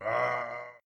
/public-share/packwiz-modpacks/SMCNV-modpack/resourcepacks/SMCNV-pack/assets/minecraft/sounds/mob/zombie_villager/
death.ogg